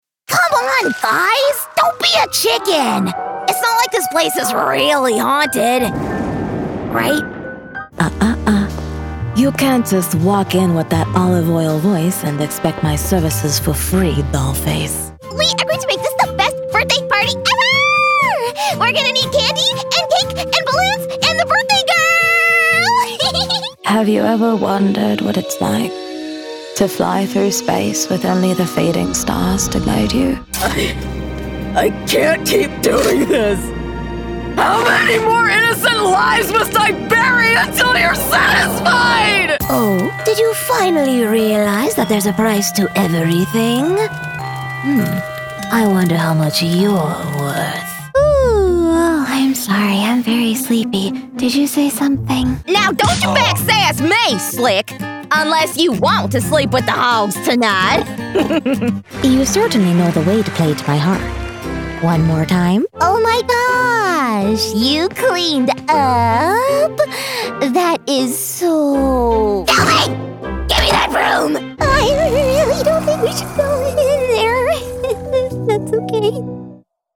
Animation-and-Video-Game-Demo-Reel.mp3